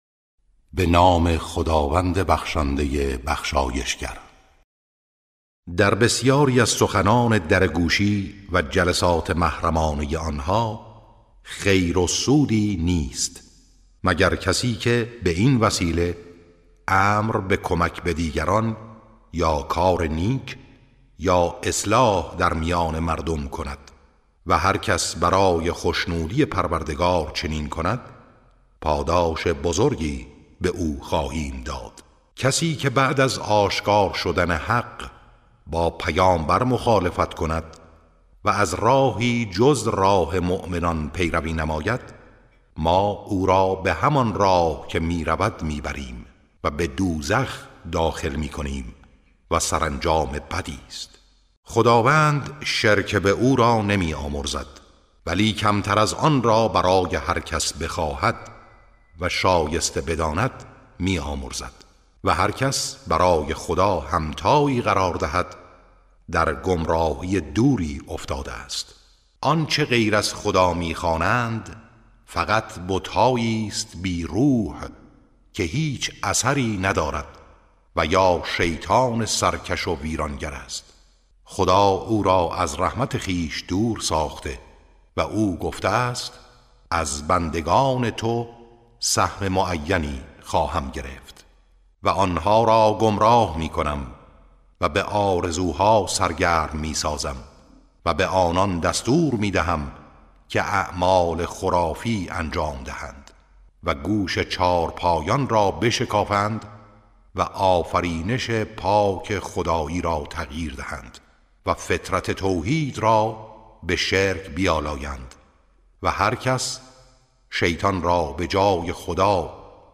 ترتیل صفحه 97 از سوره نساء(جزء پنجم)